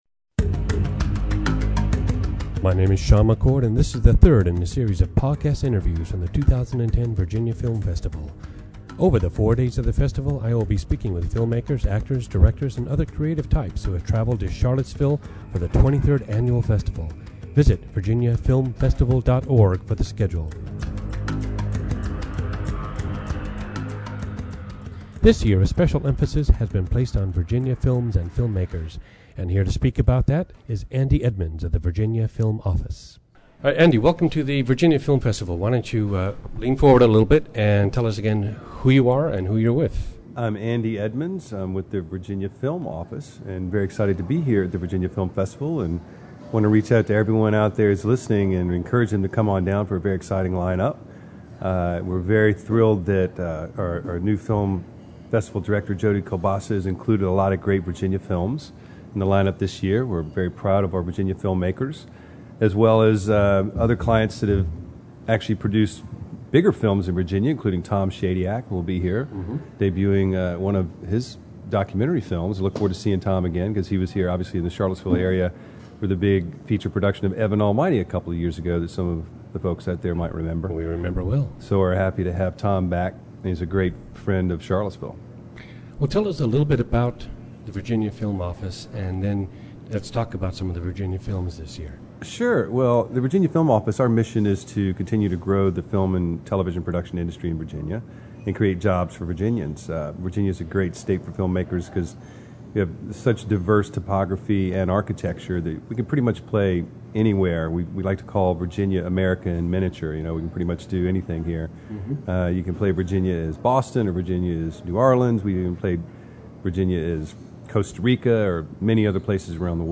This is the third in a series of podcast interviews from the 2010 Virginia Film Festival.